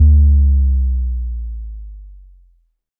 fnl/Assets/Extensions/Advanced_UI/User_Interface/Chill_Melodic/Bass Power Off 1.wav at master
Bass Power Off 1.wav